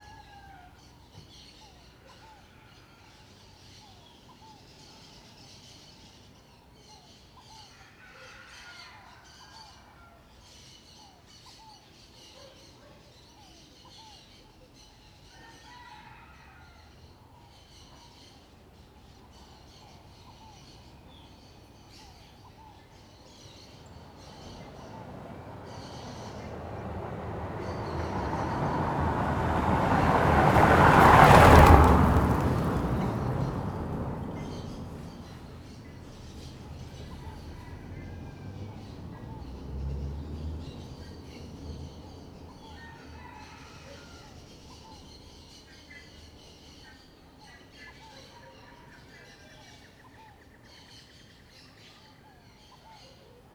Ambiencia rua de manha passaros galinha carro passa muito perto Ambiente externo , Animais , Galo , Moto , Pássaros , Rua , Trânsito , Vassoura Goiás Velho Stereo
CSC-04-040-LE - Ambiencia rua de manha passaros galo carro passa muito perto.wav